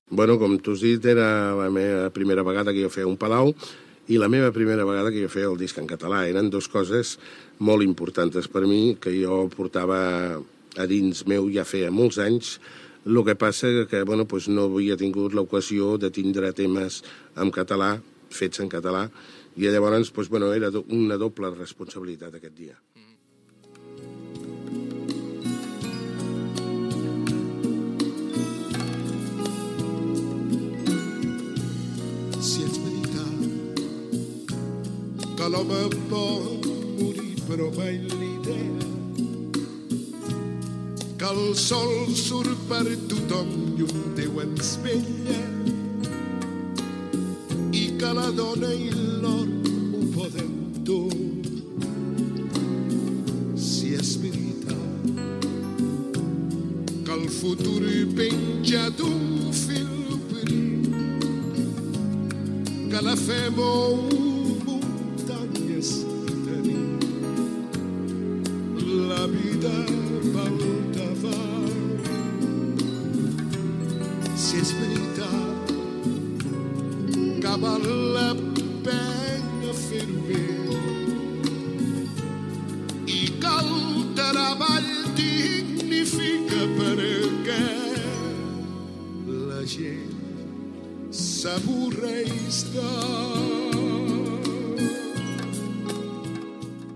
Entrevista al cantant Moncho (Ramon Calabuch i Batista) després de la seva actuació al Palau de la Música Catalana i haver presentat el disc "Paraules d'amor"